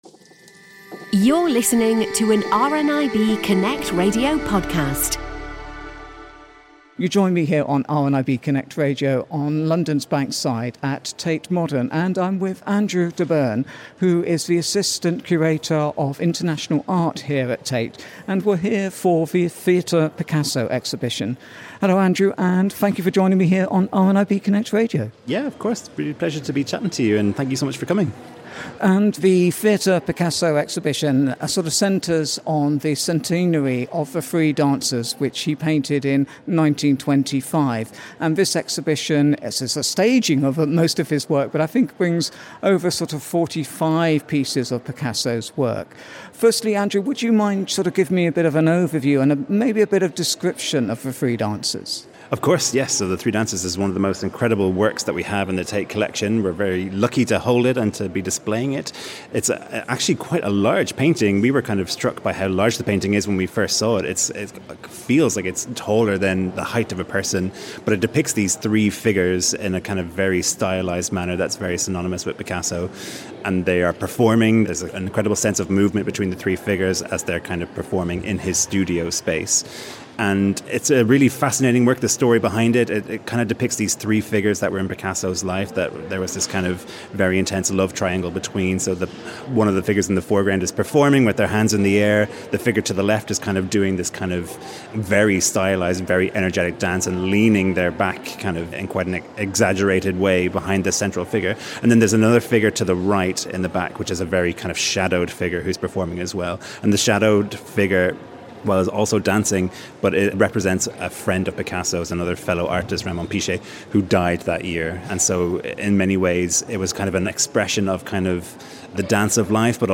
At the Press view of ‘Theatre Picasso’ on Monday 15 September 2025